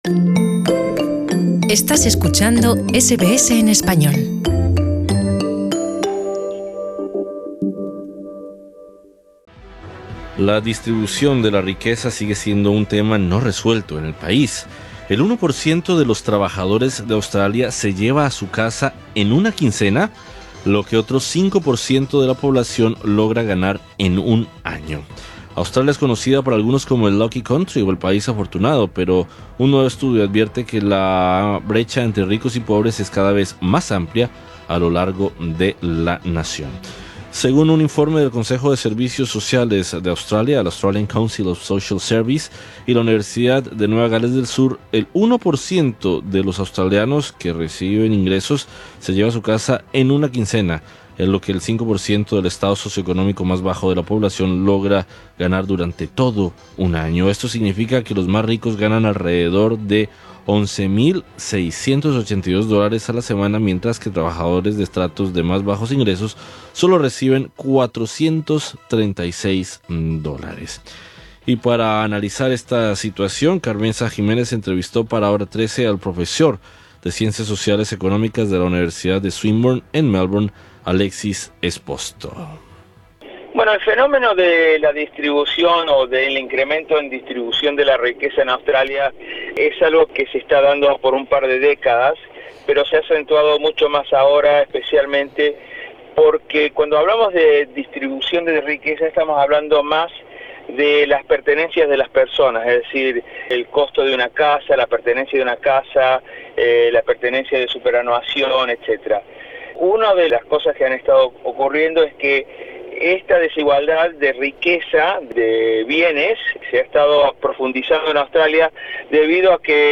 la entrevista con el economista